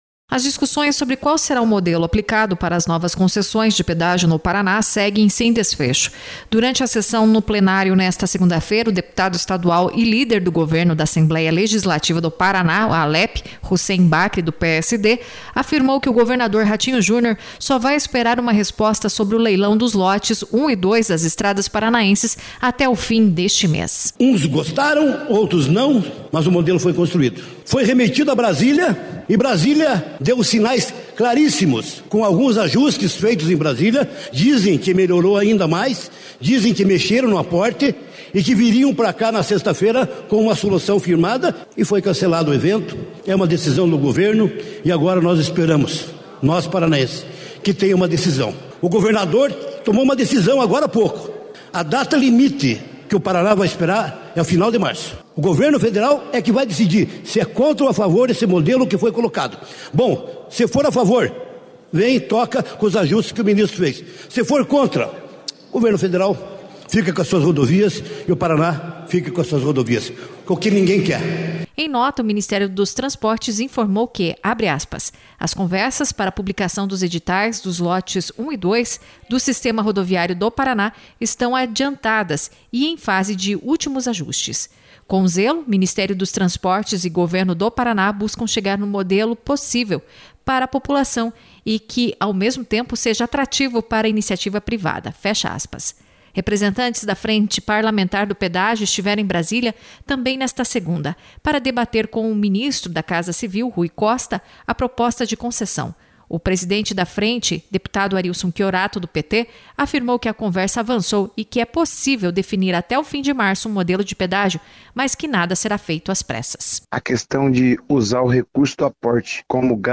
Líder do Governo na Assembleia Legislativa, o deputado Hussein Bakri (PSD) informou na sessão desta segunda-feira (6) o prazo para que o Governo Federal tome uma decisão a respeito do pedágio no Paraná: final de março. Se isso não ocorrer até lá, o Estado assumirá a responsabilidade sobre as rodovias PRs da nova concessão, enquanto as BRs ficarão sob controle da União.